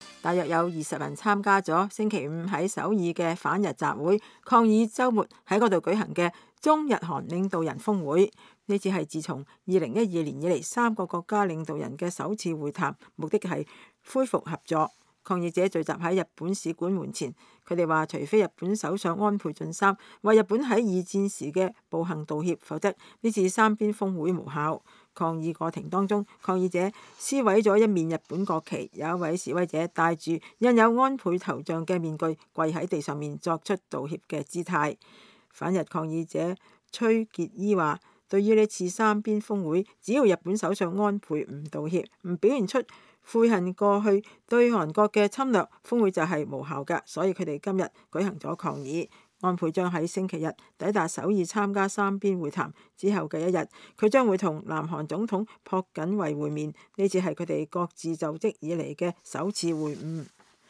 約有20人參加了周五在首爾的反日集會，抗議周末在那裡舉行的中日韓領導人峰會。